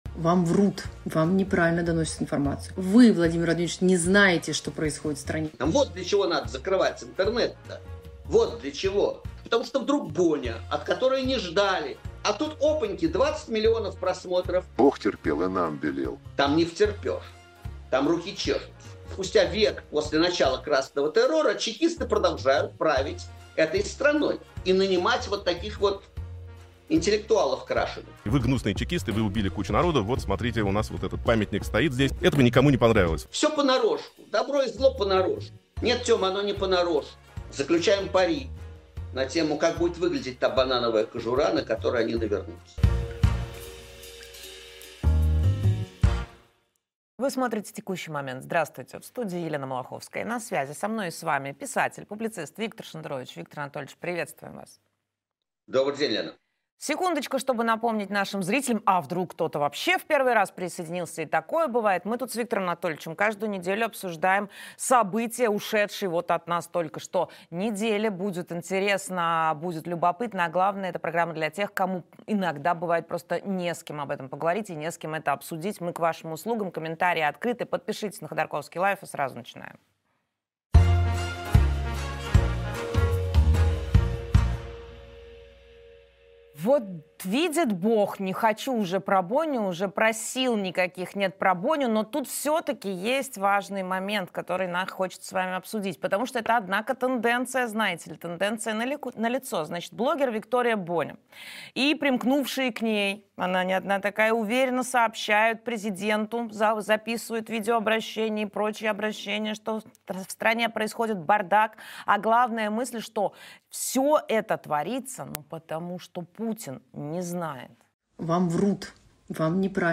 Виктор Шендерович писатель